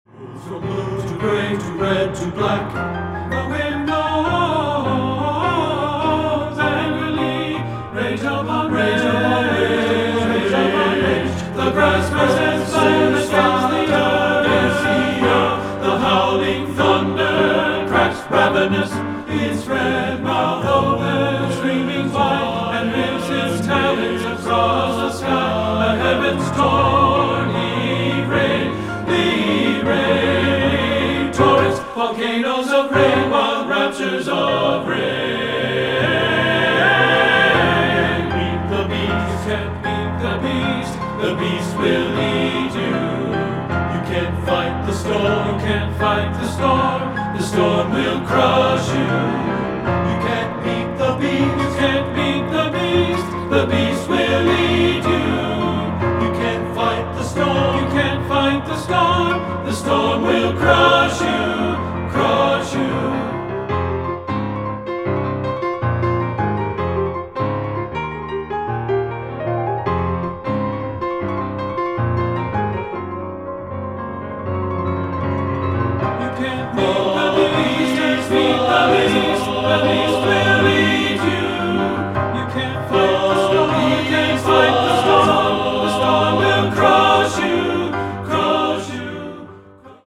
Choral Concert/General
SATB